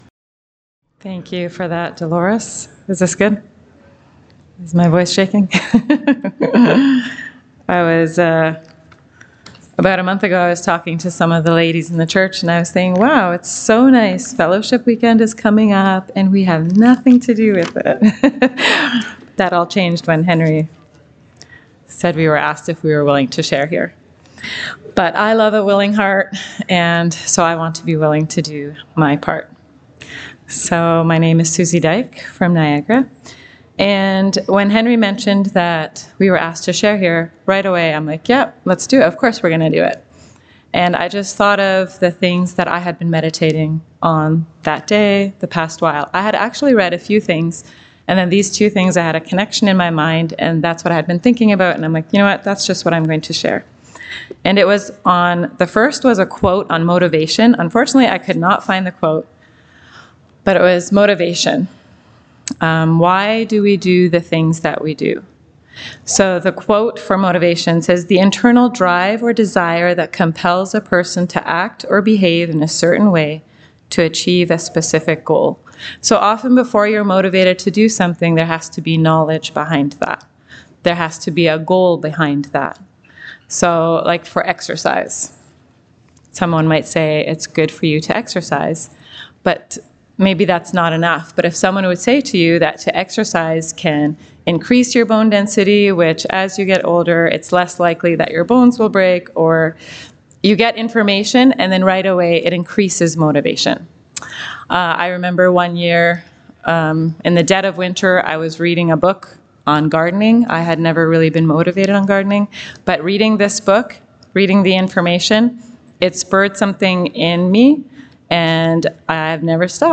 Saturday Morning Ladies Message
Service Type: Fellowship Weekend